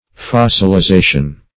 Fossilization \Fos`sil*i*za"tion\, n. [Cf. F. fossilisation.]